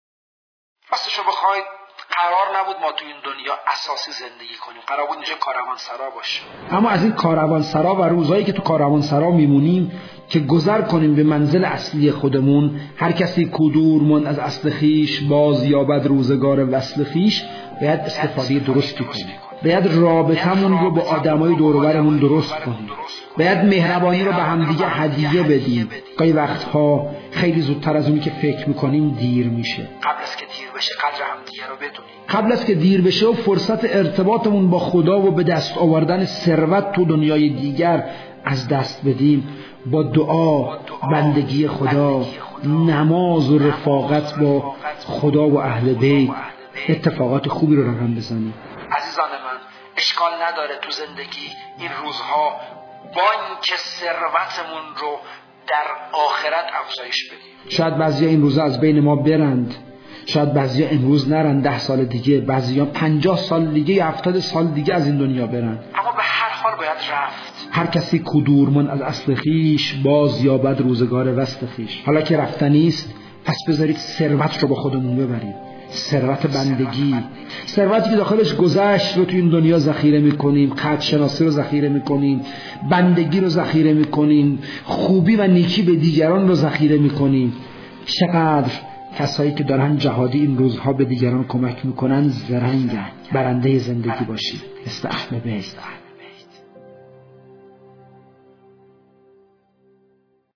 فرازی از سخنان